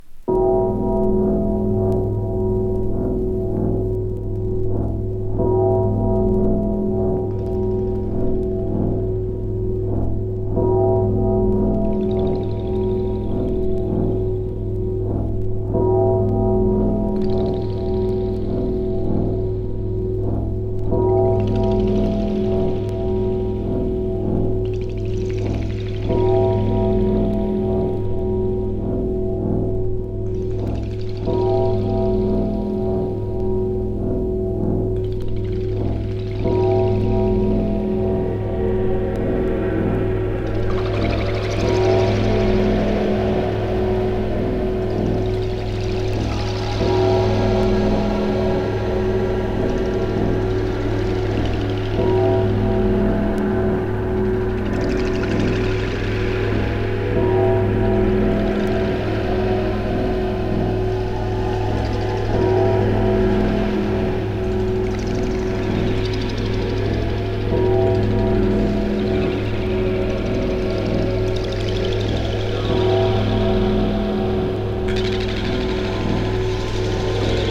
体がほぐれるようなAmbient〜緊張感たっぷりのIndustrialチューンまで。